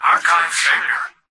"Archive failure" excerpt of the reversed speech found in the Halo 3 Terminals.
H3_tvox_no3_archivefailure_(unreversed_trimmed).mp3